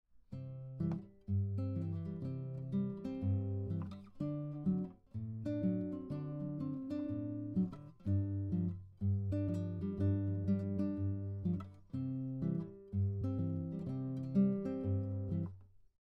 This fingerpicking style is characterized by a repeated bass pattern, while playing chords and melody at the same time.
Here is one final rhythmic variation for a Travis picking style pattern.
Travis picking pattern 7 - rhythm variation